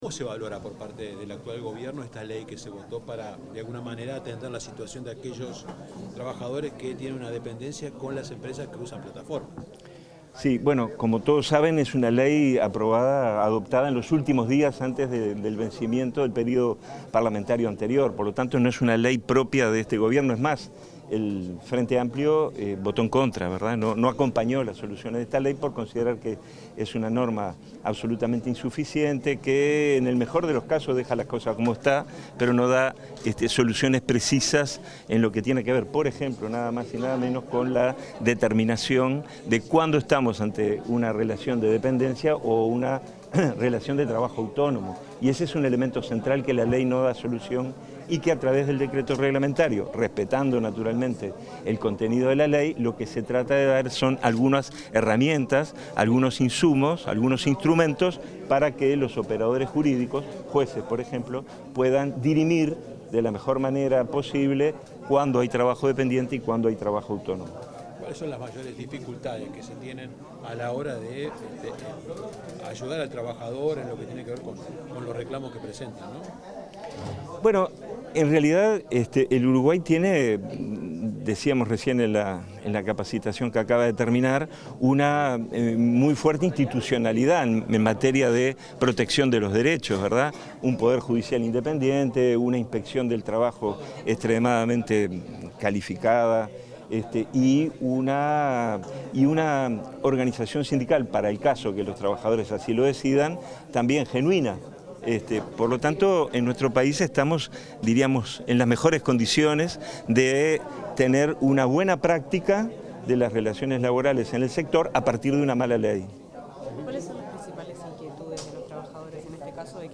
Declaraciones de subsecretario de Trabajo, Hugo Barreto